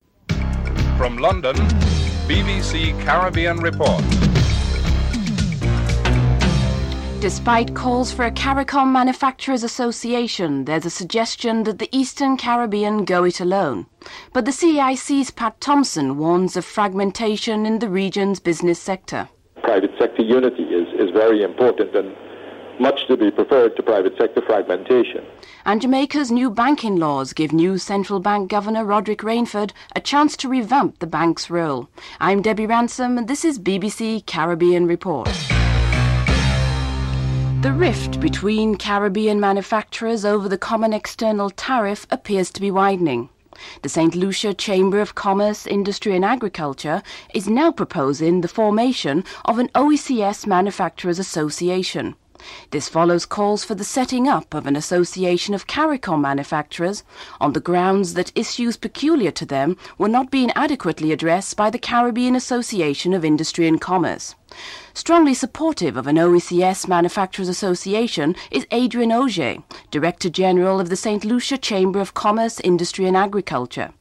1. Headlines (00:00-00:36)
7. Former West Indies Captain Viv Richards looks forward to the upcoming test series between a younger West Indies team and Australia. (11:16-13:55)